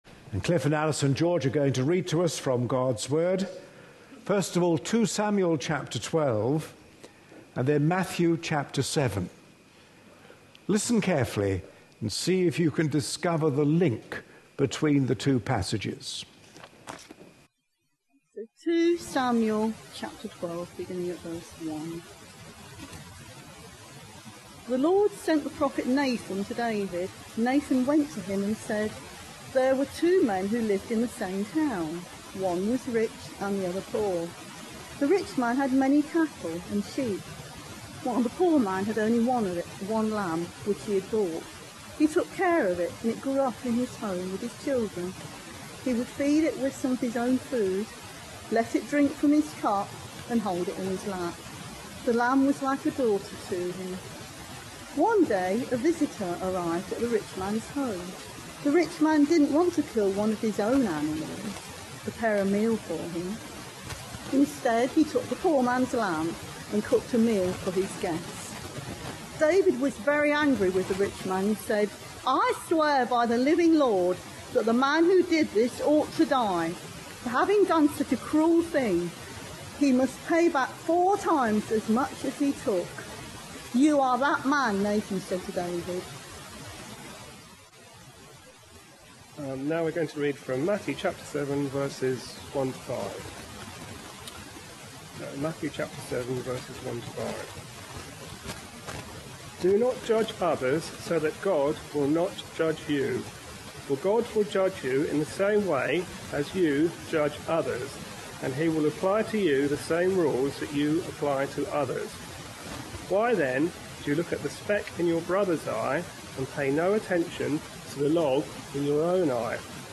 A sermon preached on 10th June, 2012, as part of our Red Letter Words series.